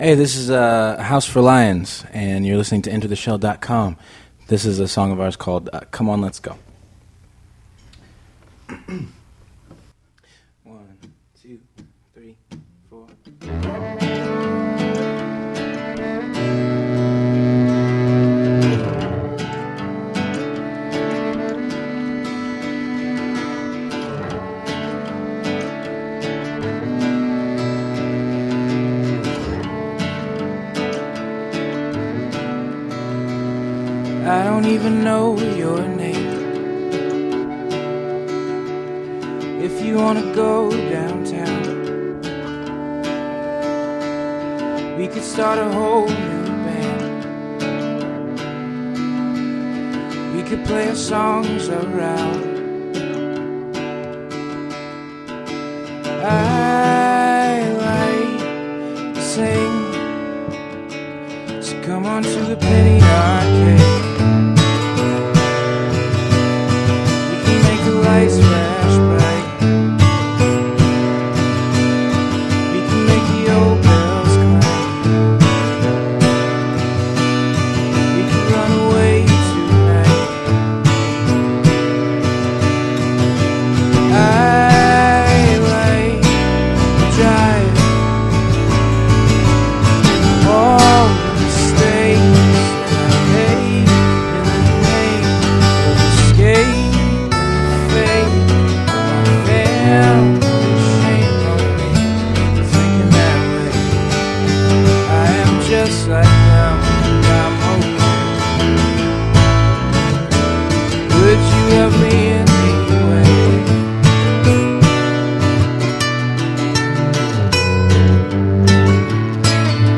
Thanks Again to NoHo2 Studios for providing the space!